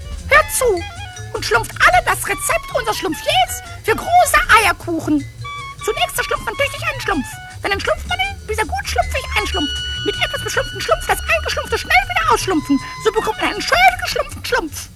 Rezept-Vorleser: